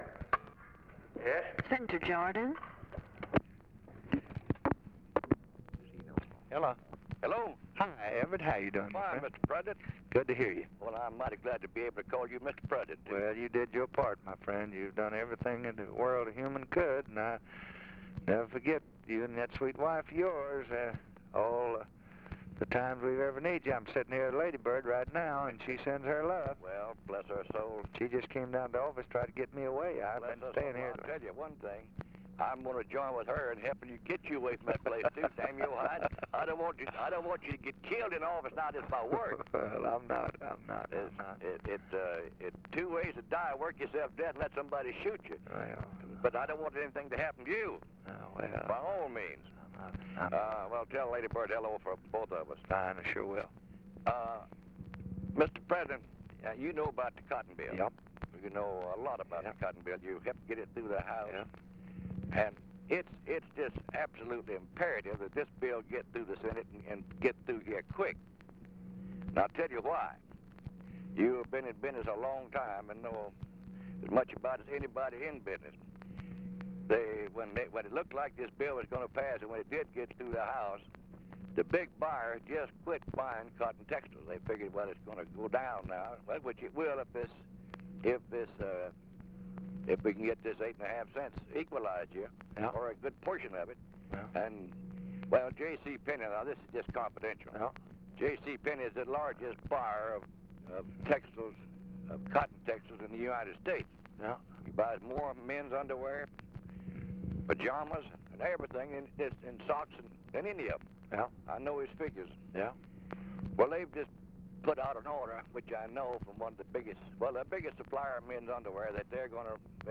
Conversation with B. EVERETT JORDAN, December 6, 1963
Secret White House Tapes